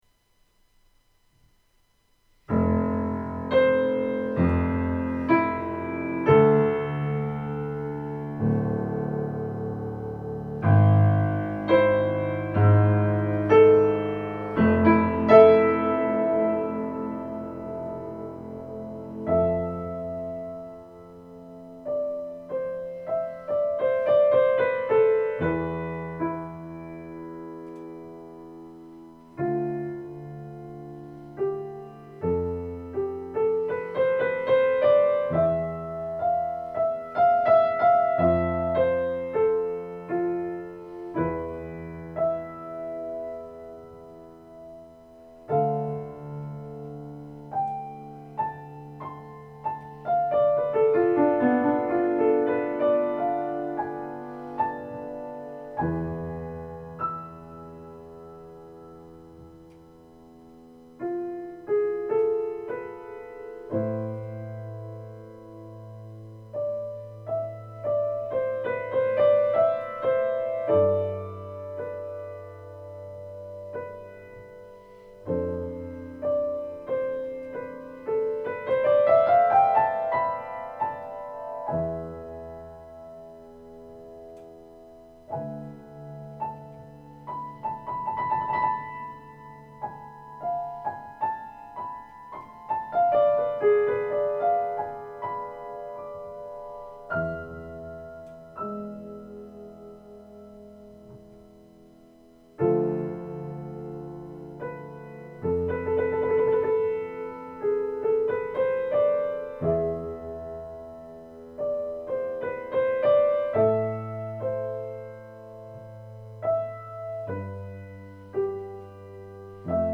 Deze ervaringen hebben me geïnspireerd om dit meerdelige werk te schrijven, het zijn sfeerbeelden in meditterane sfeer.